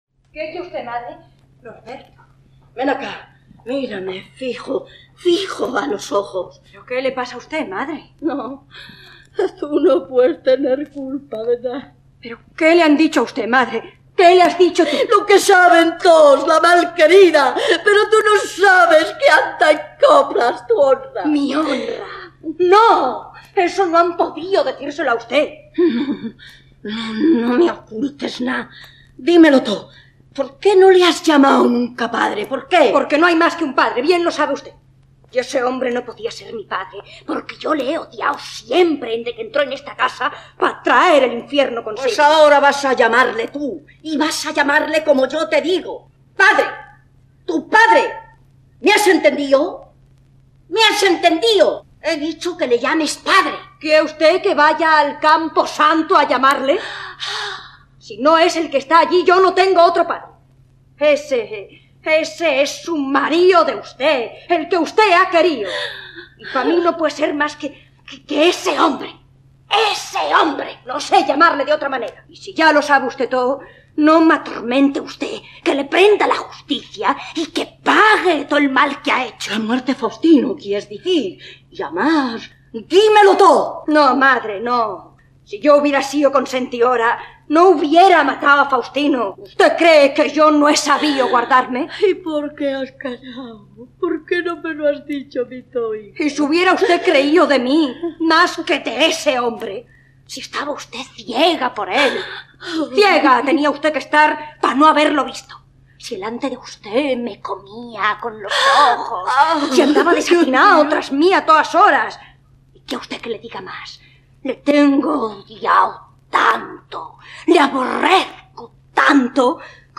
Teatro invisible: La Malquerida (interpreta La Acacia)